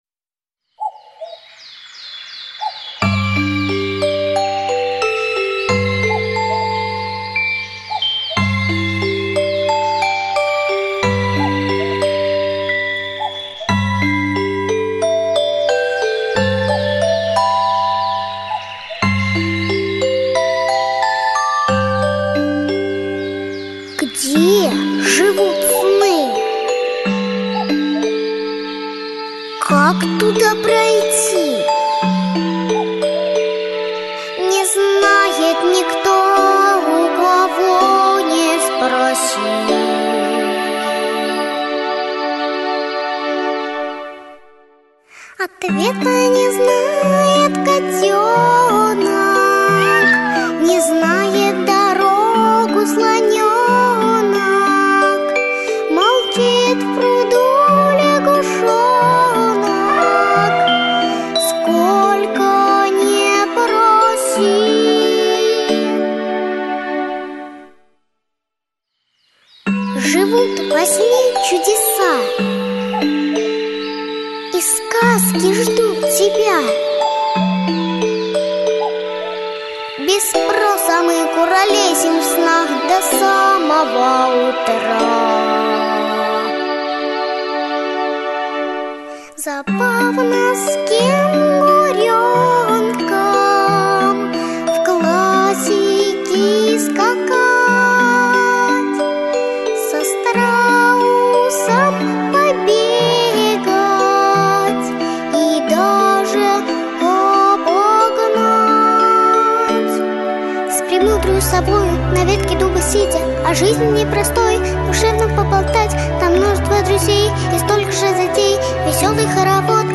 Aудиокнига Где живут сны Автор Надежда Александровна Белякова.